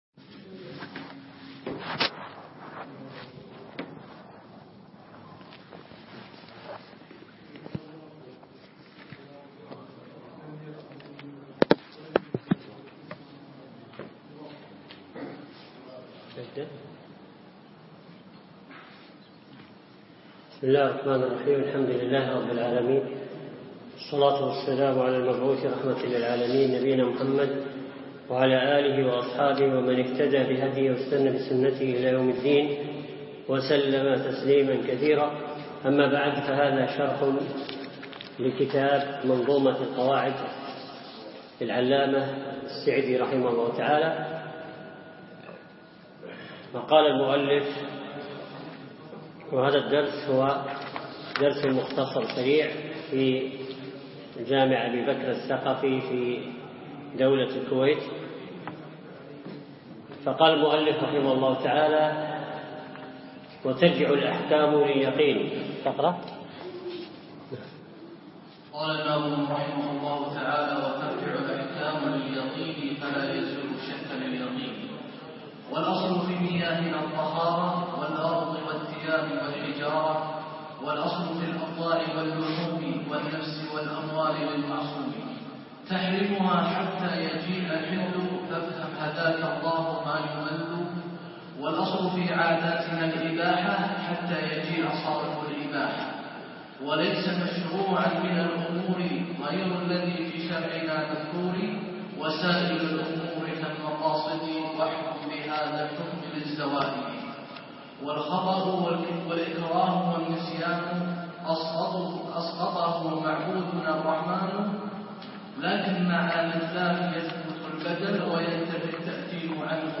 أقيم الدرس بعد مغرب الجمعة 6 3 2015 في مسجد أبي بكرة الثقفي منطقة العارضية